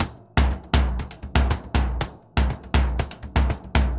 Bombo_Salsa 120_1.wav